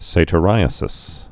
(sātə-rīə-sĭs, sătə-)